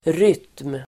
Uttal: [ryt:m]